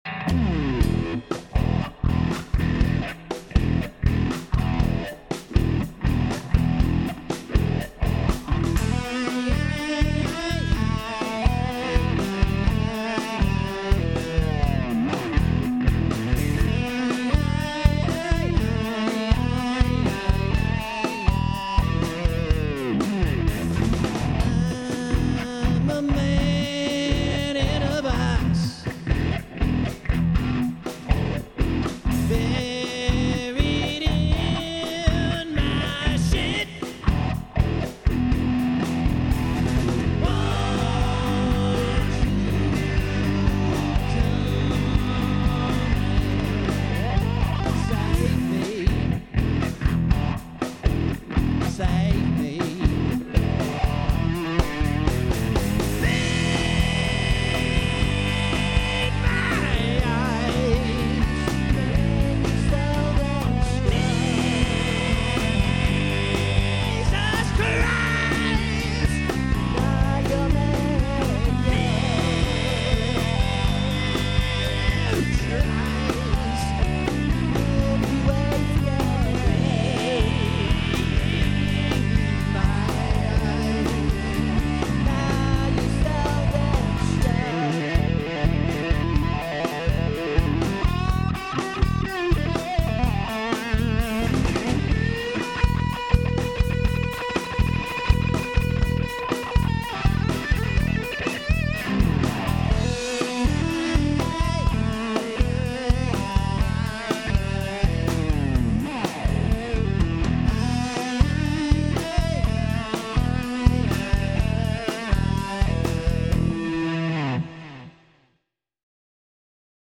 vocals and drums
bass
guitar & background vocals